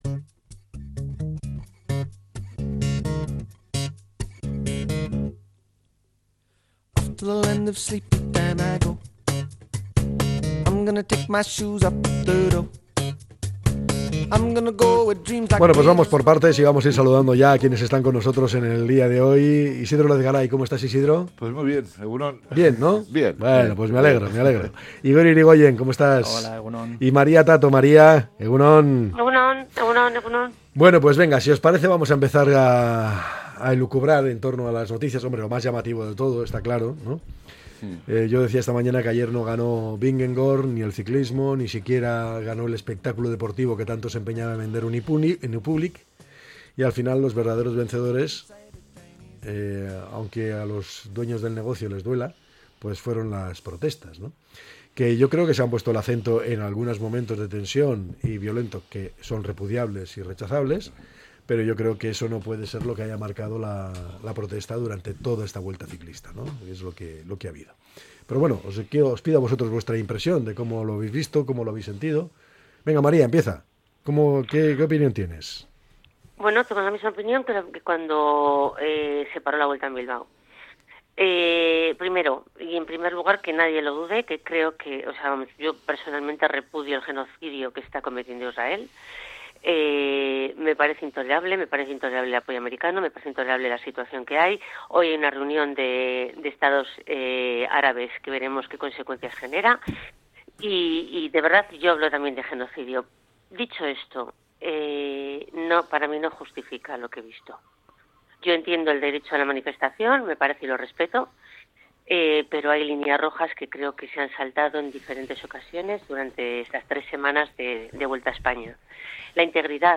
La Tertulia 15-09-25.